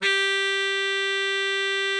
bari_sax_067.wav